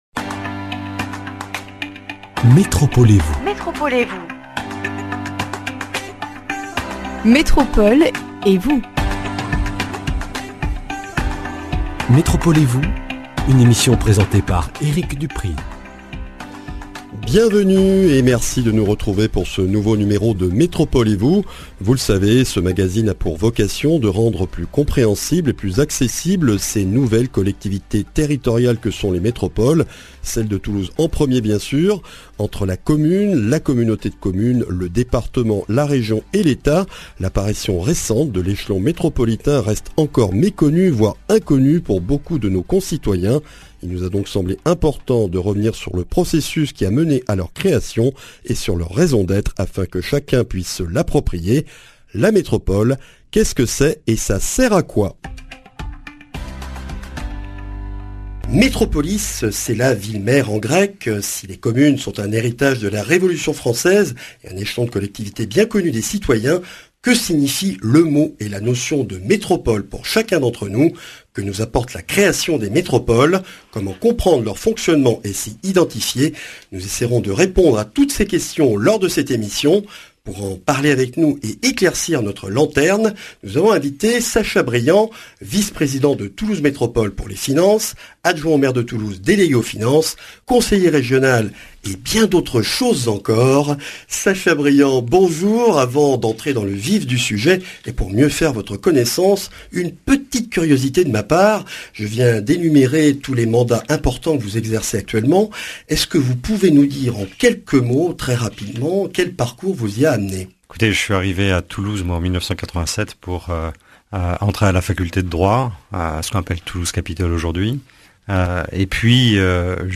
Une émission avec Sacha Briand, conseiller régional, adjoint aux finances à la mairie de Toulouse et Vice-président de Toulouse Métropole en charge des Finances, pour se familiariser avec les domaines de compétences et les missions de Toulouse Métropole.